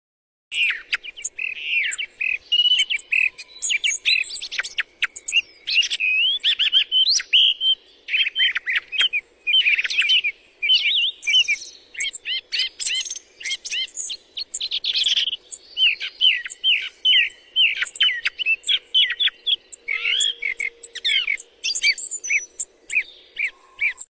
Songthrush call&song,Night song for hunting.